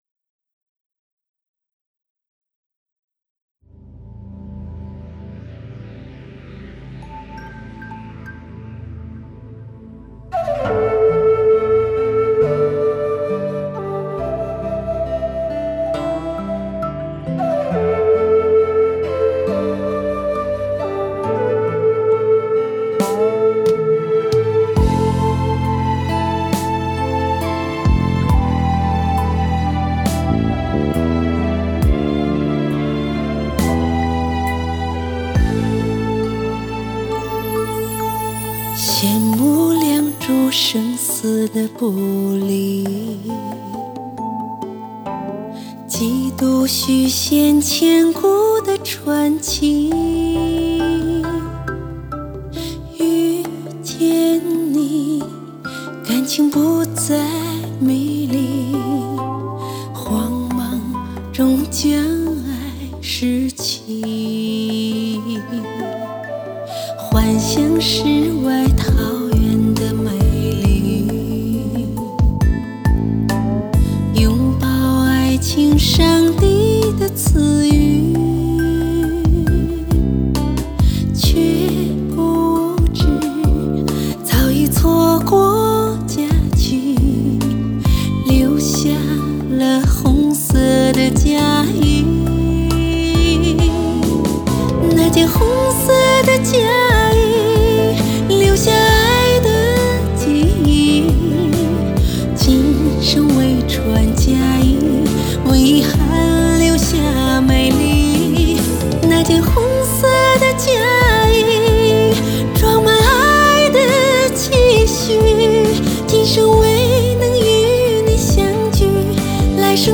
她的歌特别柔美